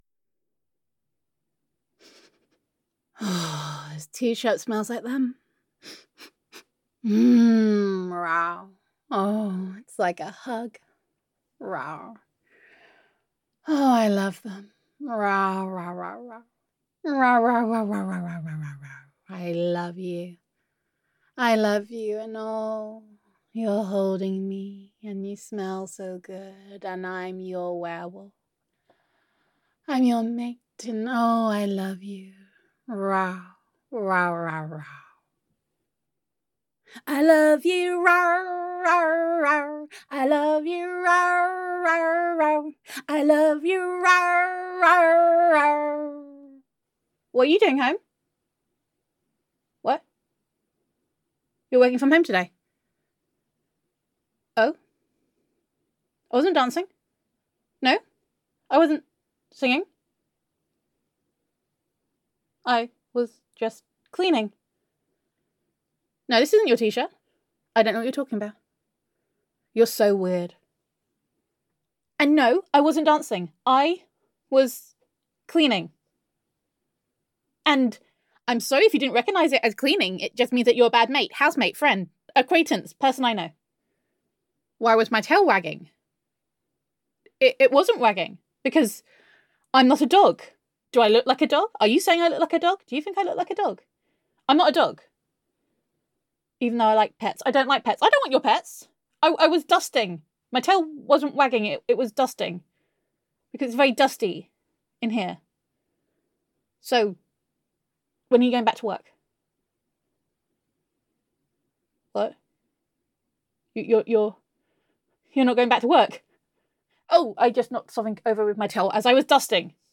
[F4A] Corona and Your Tsundere Werewolf Roommate [Happy Dancing][Tail Wagging][Cleaning][Dusting][Denial][Happy That You Are Home][Working From Home][Gender Neutral][Tsundere Werewolf Roommate Roleplay]